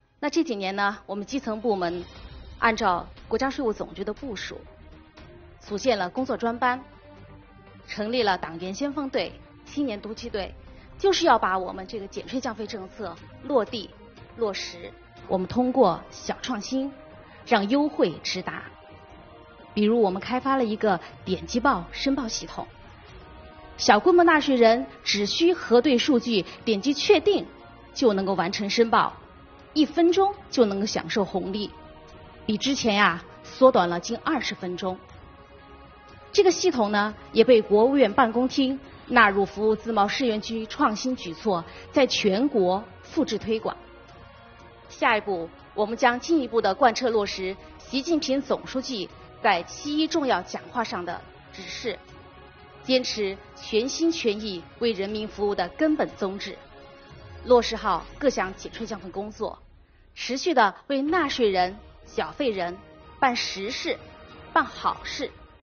7月15日，中共中央宣传部举行中外记者见面会，邀请5名税务系统党员代表围绕“坚守初心 税收为民”主题与中外记者见面交流。